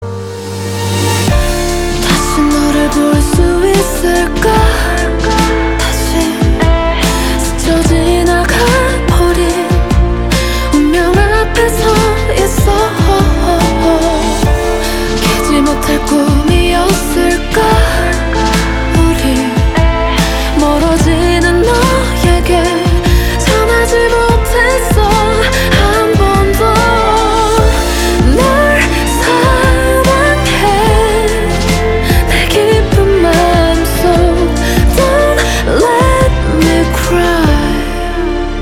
• Качество: 320, Stereo
саундтреки
романтичные
корейские